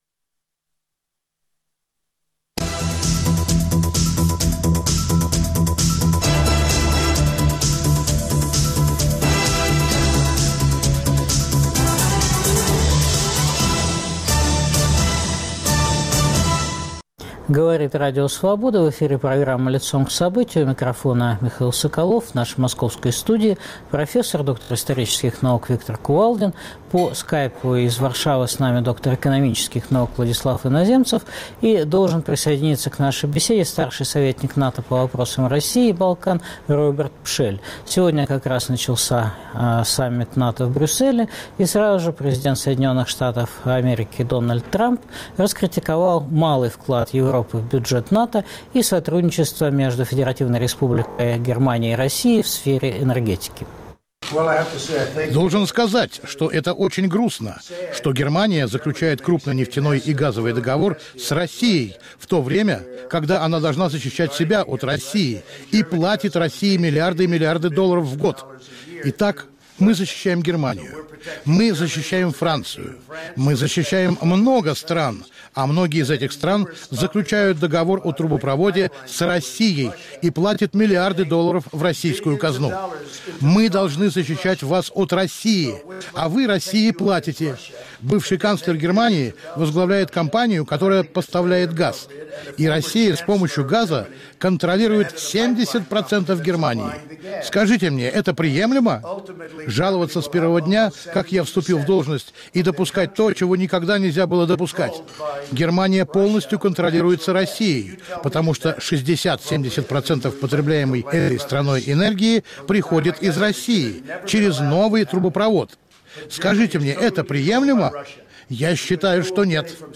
По телефону из Брюсселя